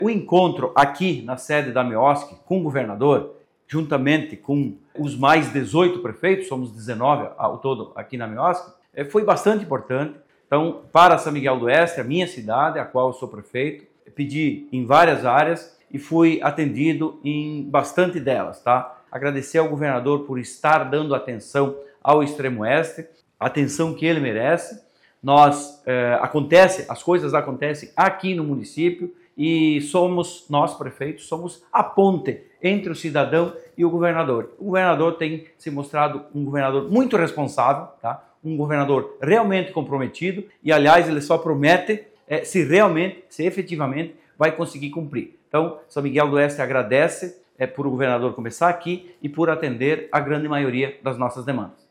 O prefeito ressalta que a maioria das demandas levadas ao governador foram atendidas pelo Estado e destaca como os recursos serão utilizados: